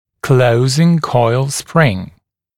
[‘kləuzɪŋ kɔɪl sprɪŋ][‘клоузин койл сприн]пружина для закрытия промежутков